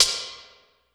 59 O HH 1 -R.wav